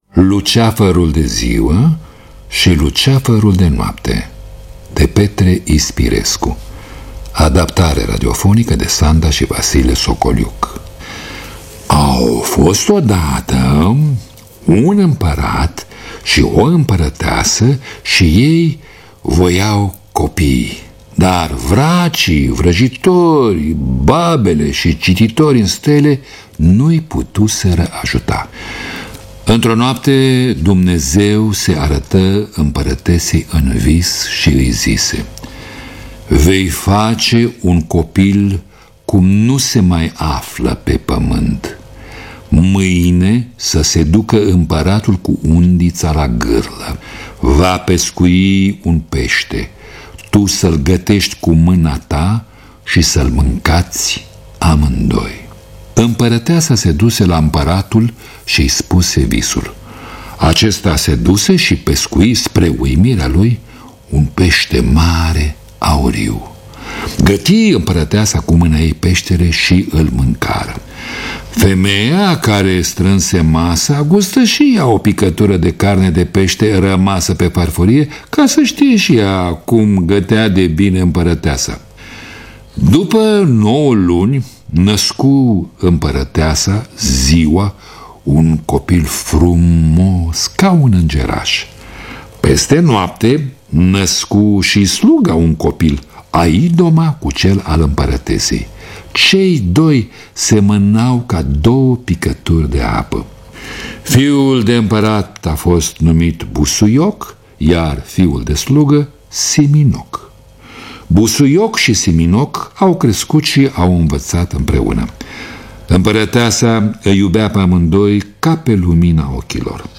Lectura: Mircea Albulescu.